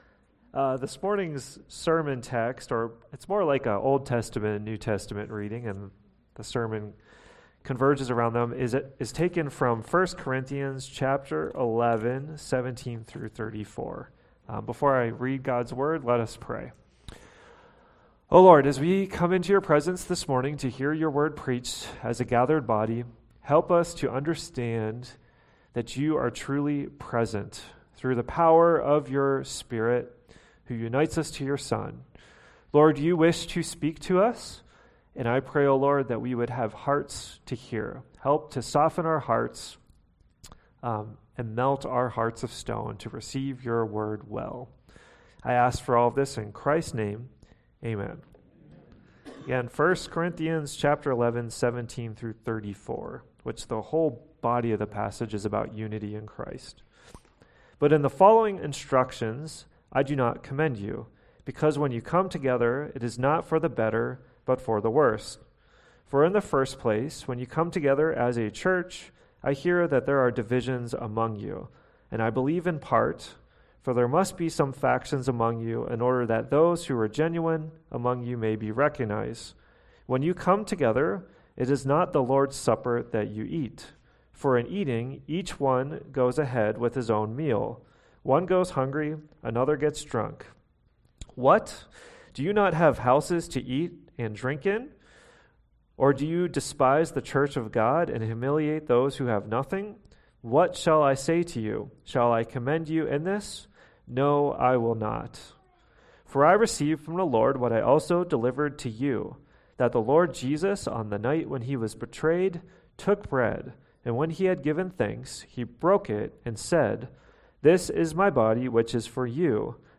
NEW SERMON